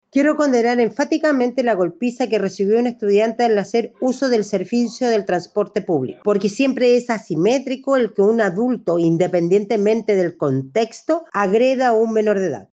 Por su parte, el seremi de Educación de La Araucanía, Marcela Castro, dijo que nada justifica la agresión a un menor de edad.